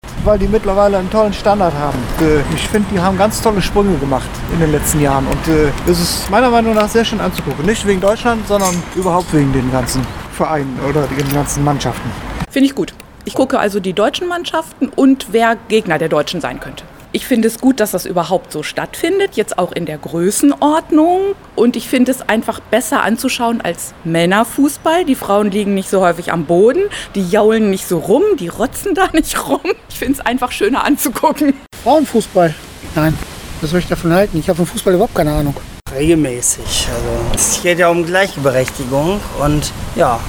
Hameln: UMFRAGE FRAUENFUSSBALL
Heute Abend um 21 Uhr spielt die deutsche Frauen-Nationalmannschaft gegen Frankreich. Was die Hamelner von Frauen-Fußball halten und ob sie die EM gucken, darüber haben wir uns in der Innenstadt erkundigt…